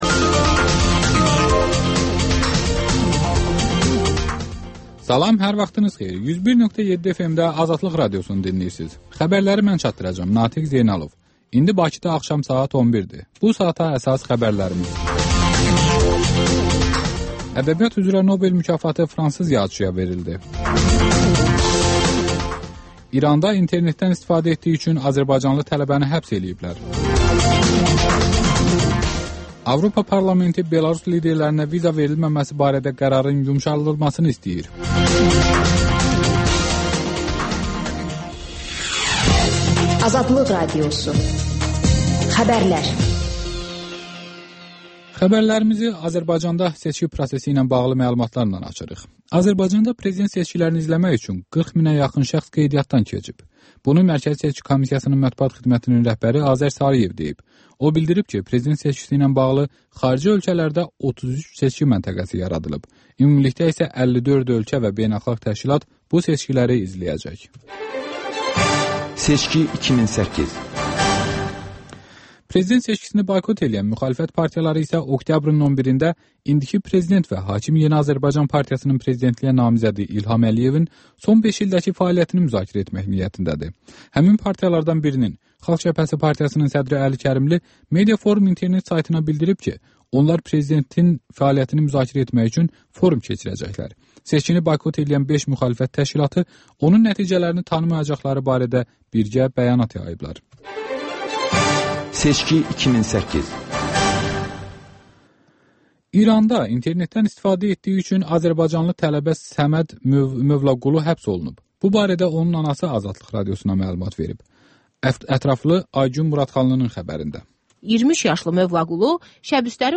Xəbərlər, RAP-TIME: Gənclərin musiqi verilişi